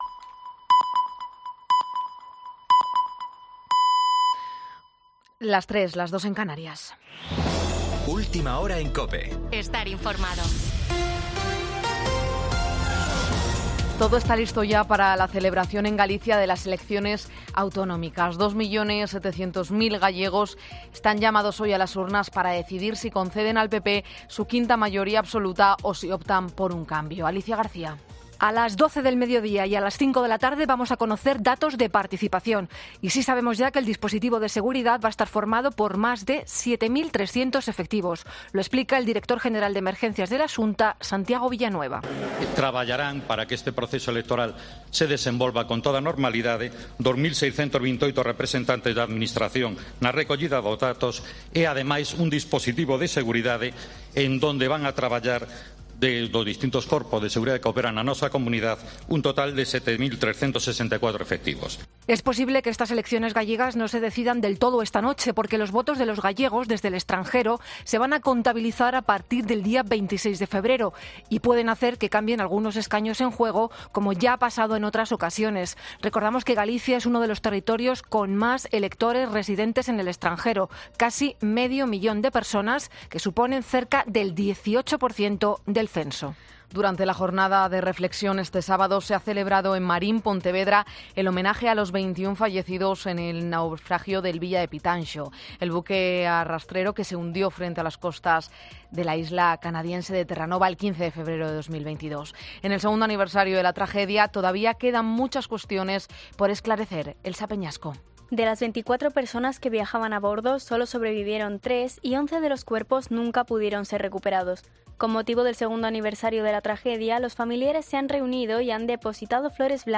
AUDIO: Boletín 03.00 horas del 18 de febrero de 2024